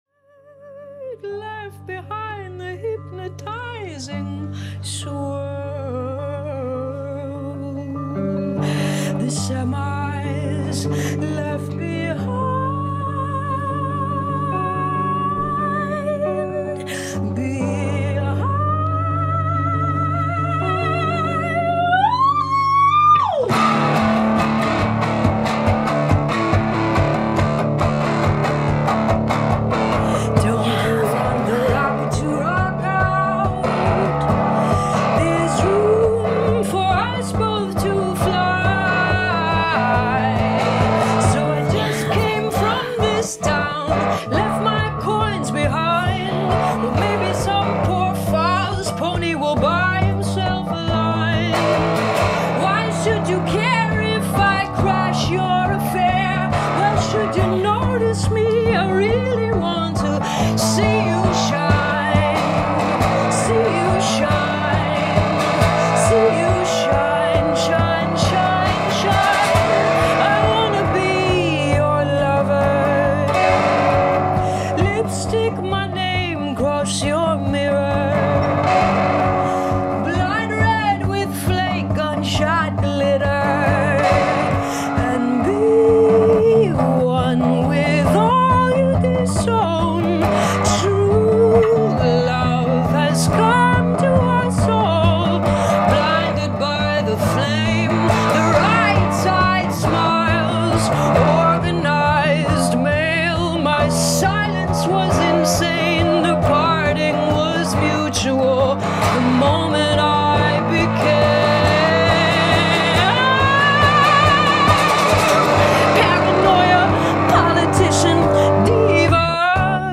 mesmerizing, astounding, hypnotic, other-worldly